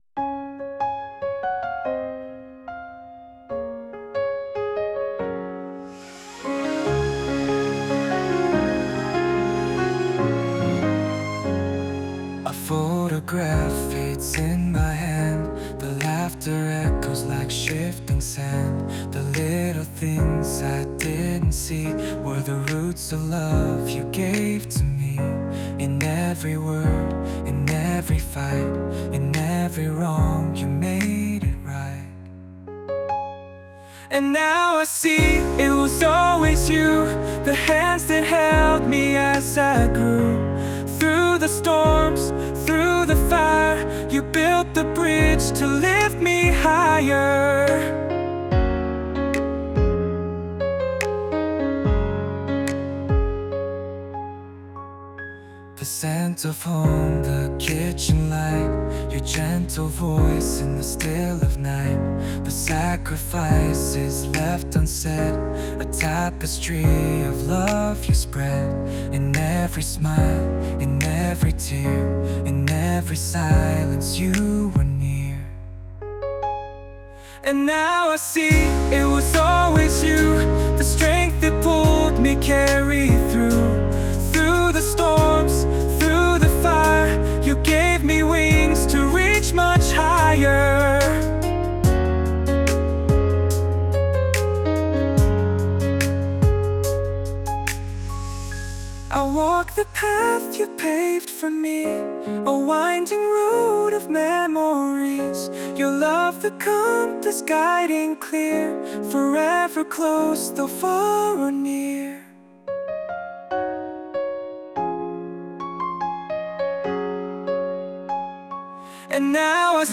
男性ボーカル洋楽 男性ボーカルプロフィールムービーエンドロール
著作権フリーオリジナルBGMです。
男性ボーカル（洋楽・英語）曲です。